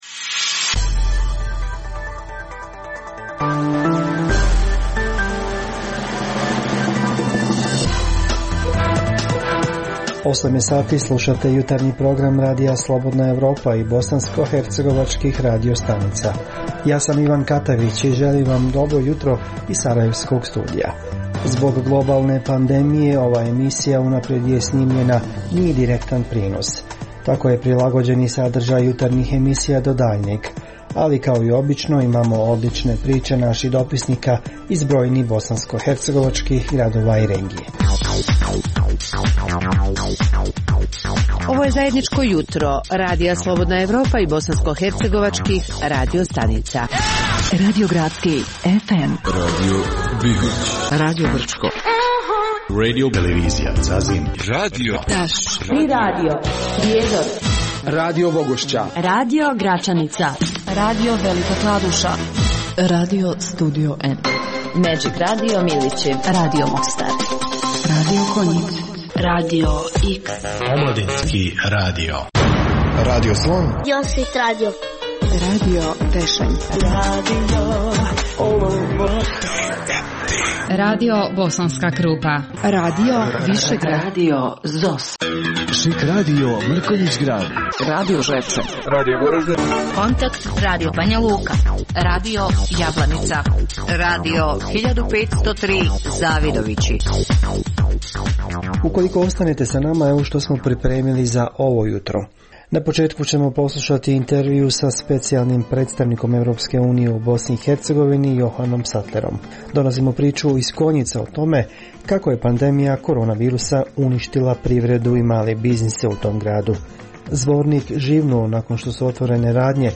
Zbog pooštrenih mjera kretanja u cilju sprječavanja zaraze korona virusom, ovaj program je unaprijed snimljen. Poslušajte neke od priča iz raznih krajeva Bosne i Hercegovine.